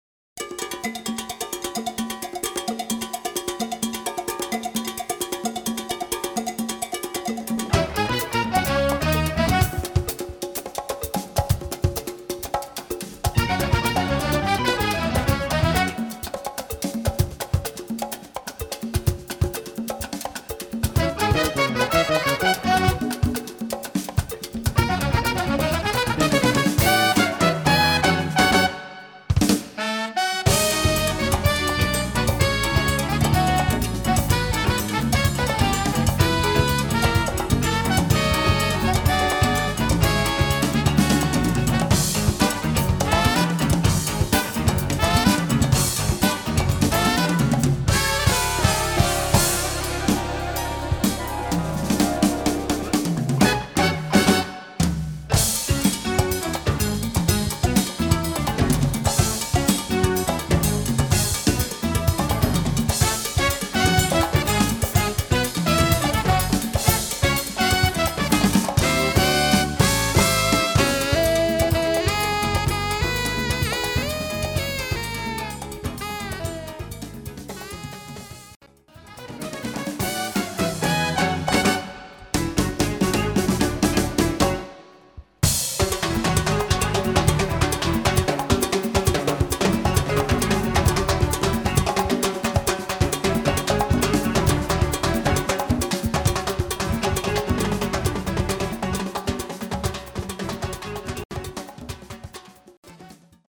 Category: little big band
Style: rumba